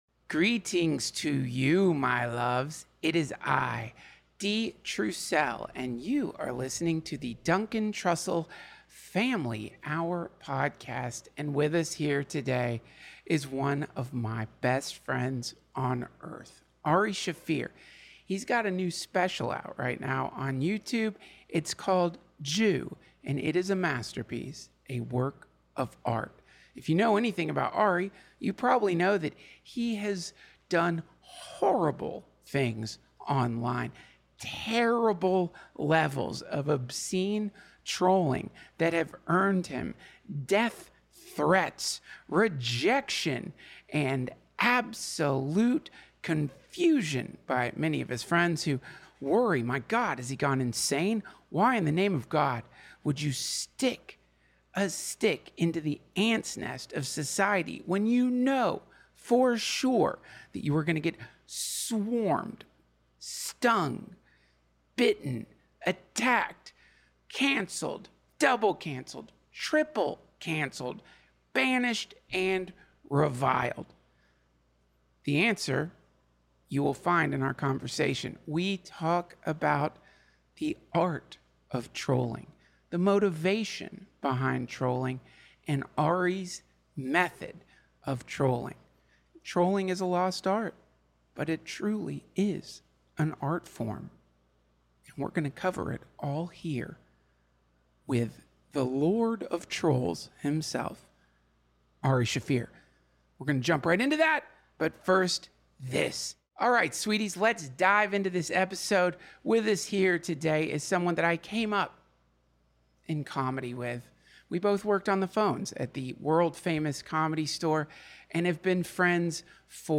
Ari Shaffir, comedian and one of Duncan's best friends in the world, re-joins the DTFH!